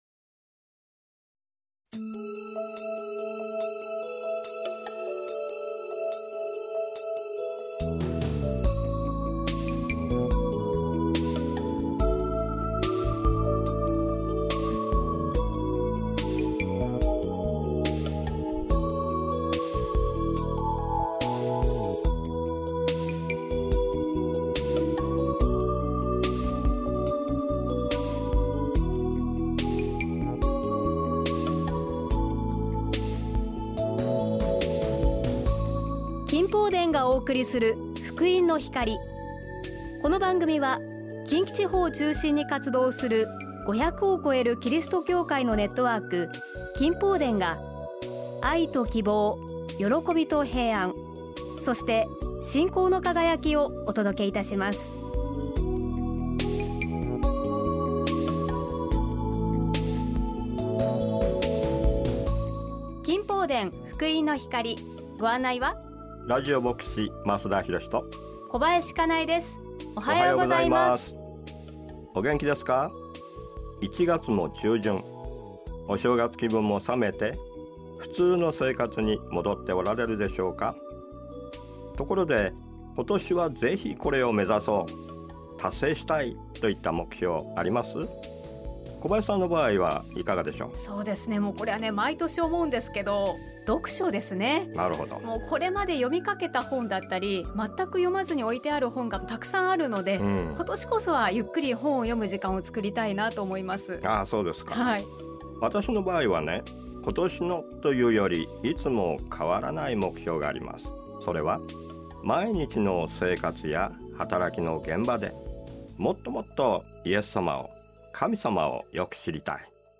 御言葉とお話